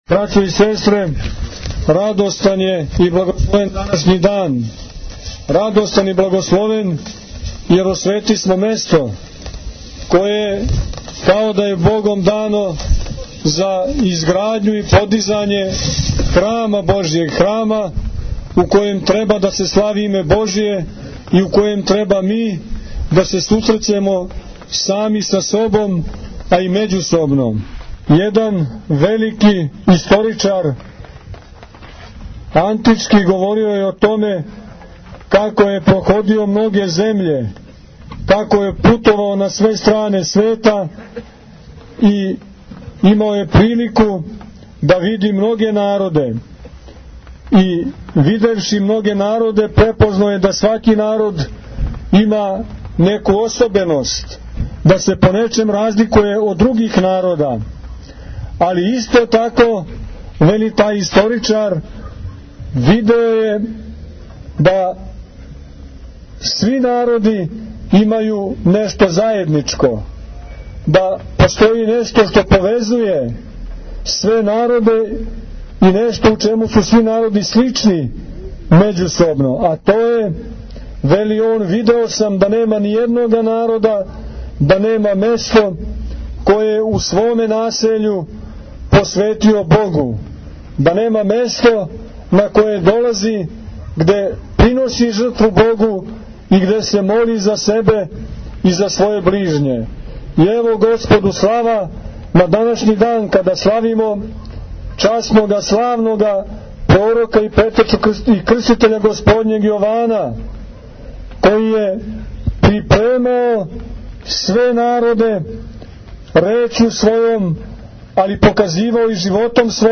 Eпископ Порфирије освештао земљиште за изградњу храма у Бачком Душанову